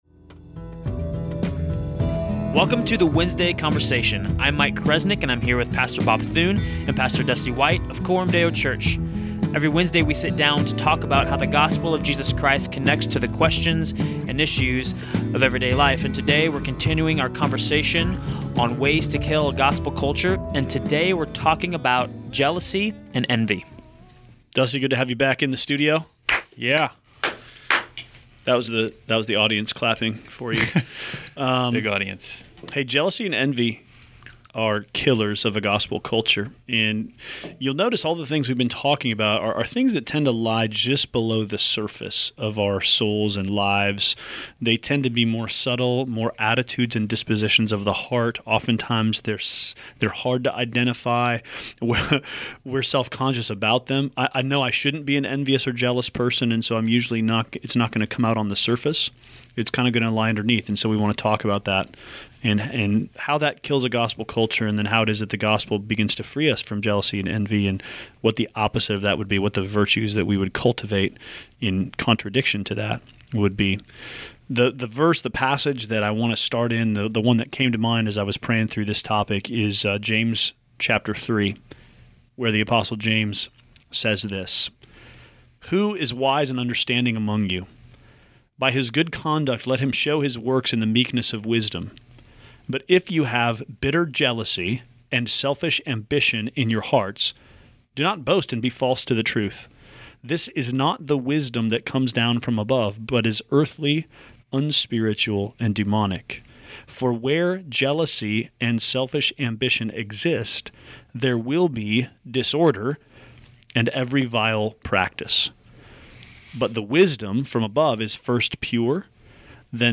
A series of conversations on Ways to Kill a Gospel Culture. Today we talked about "envy" and "jealousy."